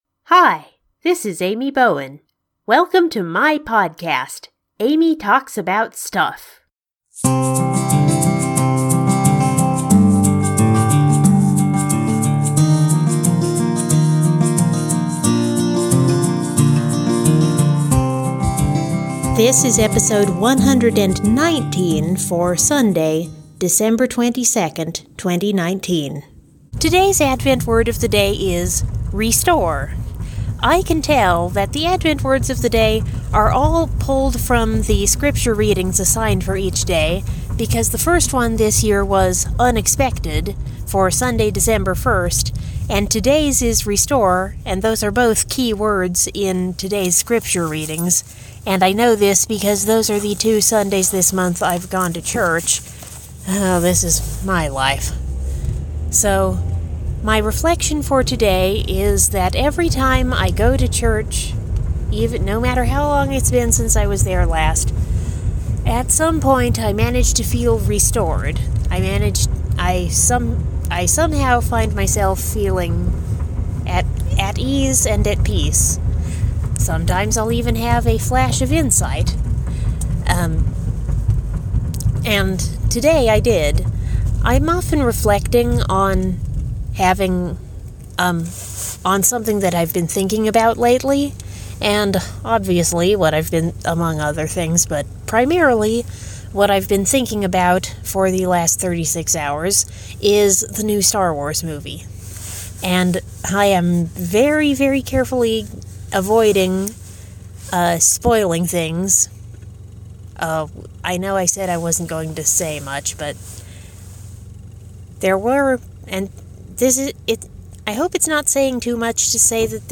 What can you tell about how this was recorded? Another reflection and another silly song for Sunday (actually recorded on Monday due to technical difficulties).